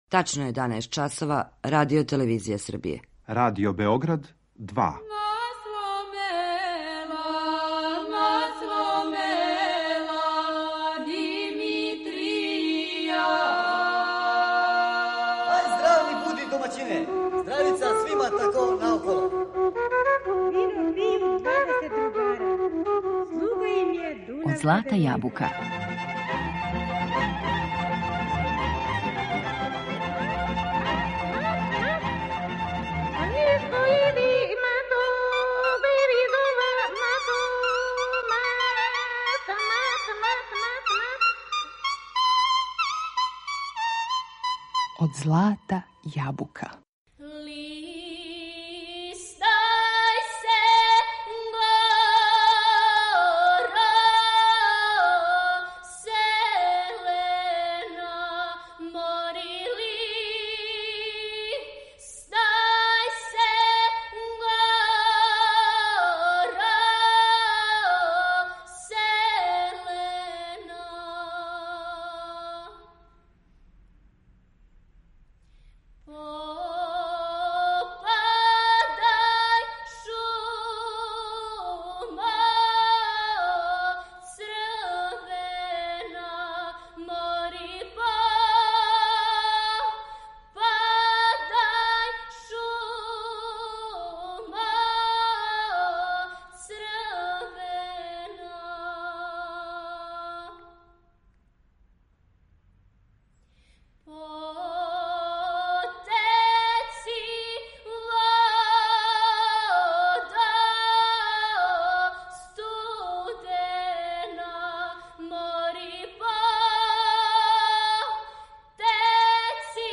У емисиј и Од злата јабука слушамо снимак првонаграђених ученика са овогодишњег републичког такмичења.